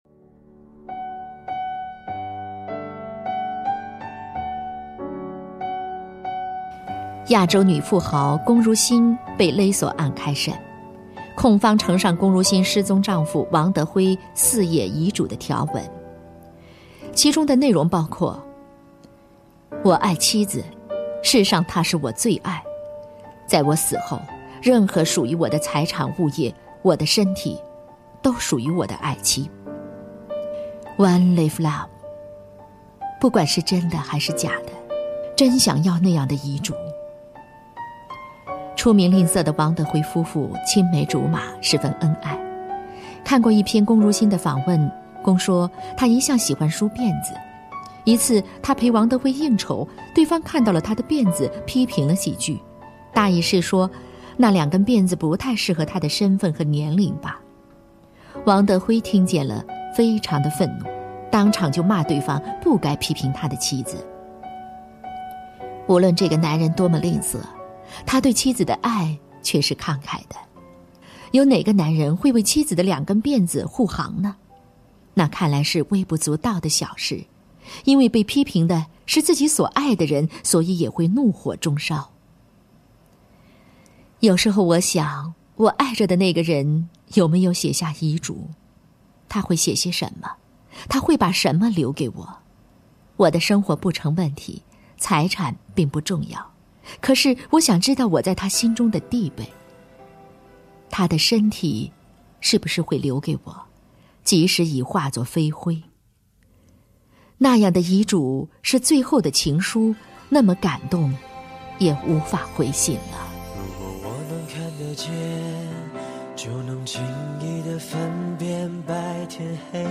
经典朗诵欣赏 张小娴：爱，从来就是一件千回百转的事 目录